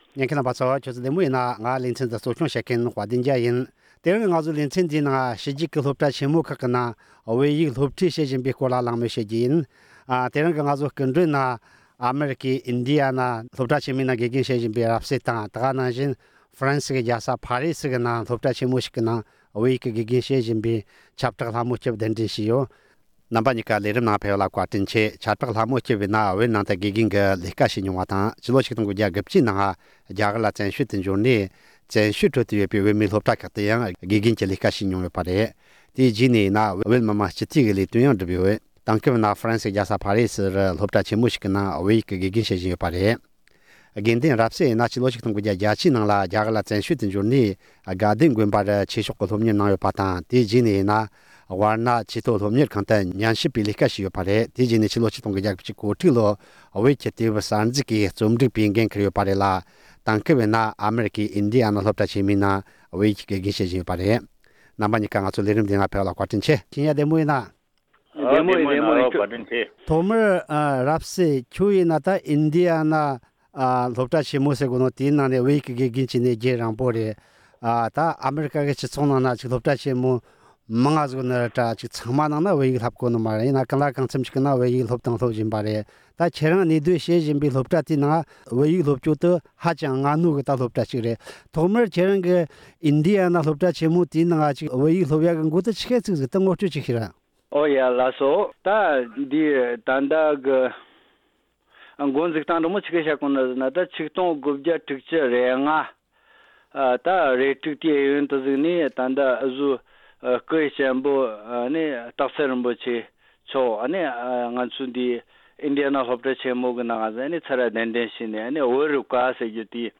ཕྱི་རྒྱལ་ཁག་གི་སློབ་གྲྭ་ཆེན་མོའི་ནང་བོད་ཡིག་གི་སློབ་ཁྲིད་གནང་ཚུལ་སྐོར་ལ་འབྲེལ་ཡོད་དང་གླེང་མོལ་ཞུས་པའི་ལས་རིམ།